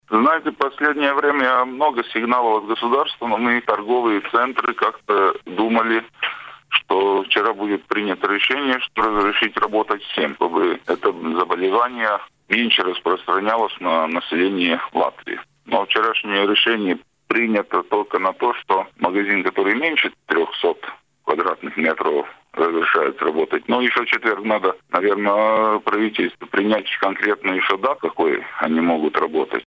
В эфире радио Baltkom говорили сегодня в том числе о снятии ограничений в торговле с 1 марта.